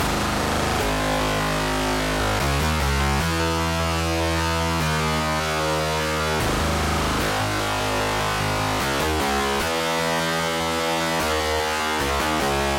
描述：基本的fm合成器短语。G小调音阶。
Tag: 150 bpm Electronic Loops Synth Loops 2.15 MB wav Key : G